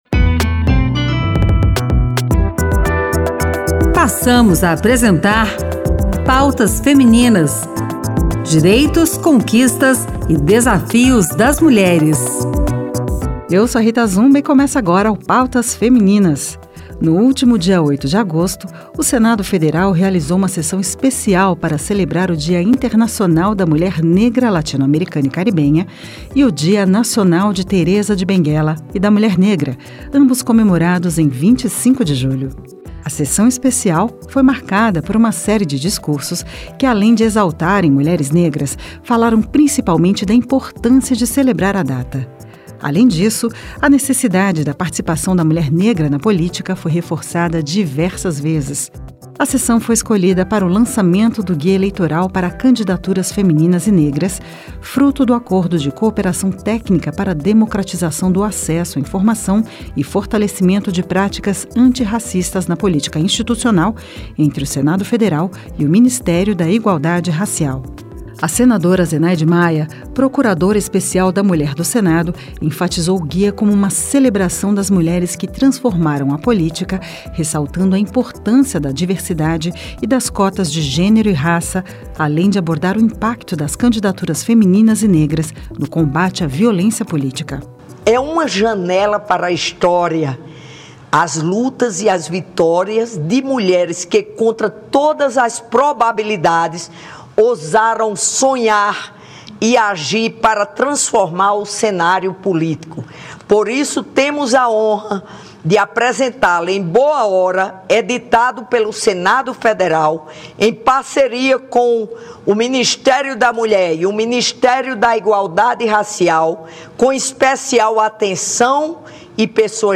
10"13' TEC – VINHETA ABERTURA TEC – BG LOC